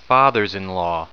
Prononciation du mot fathers-in-law en anglais (fichier audio)
Prononciation du mot : fathers-in-law